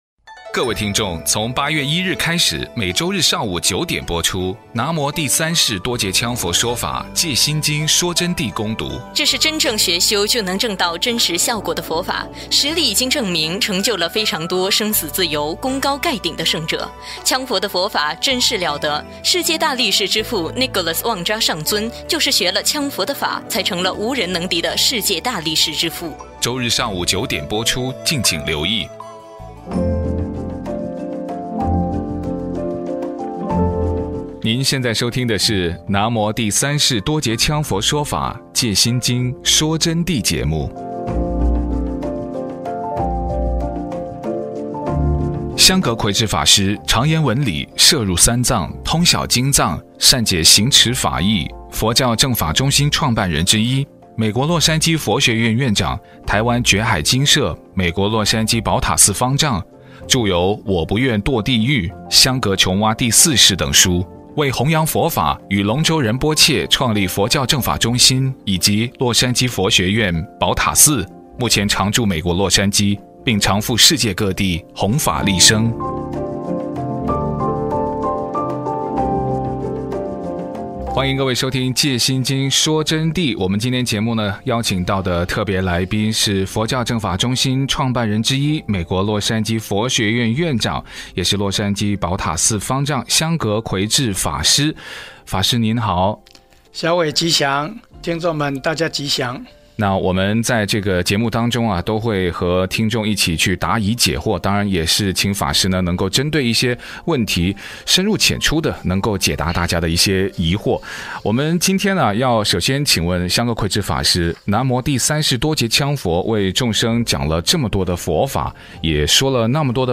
KAZN AM1300 中文广播电台每周日早上九点播出
第二阶段专访节目